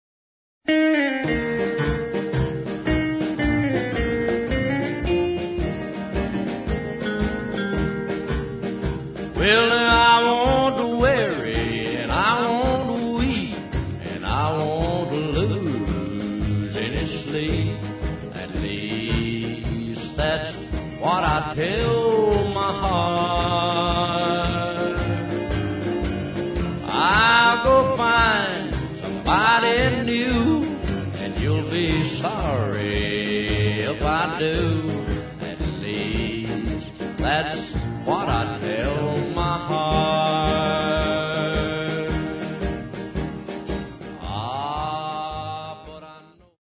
Talents : Vocals, Guitar
Style musical : Traditional Country, Honky Tonk, Rockabilly